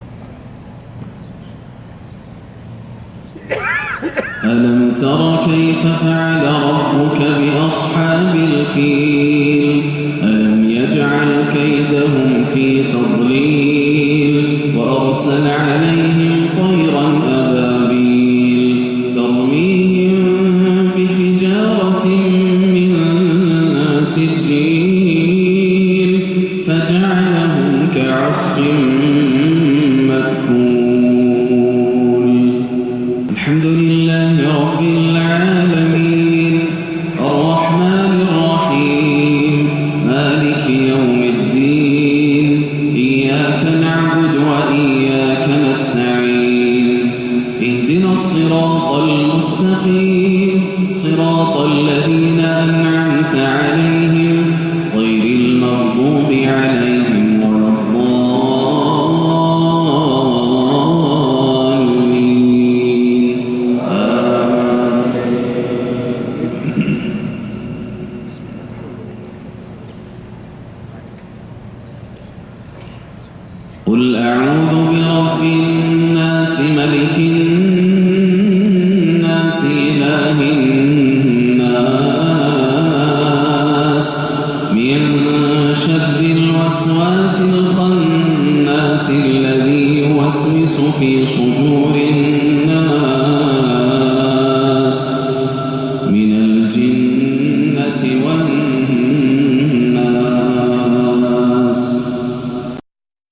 مغرب الإثنين 2- 1-1438هـ سورتي الفيل و الناس > عام 1438 > الفروض - تلاوات ياسر الدوسري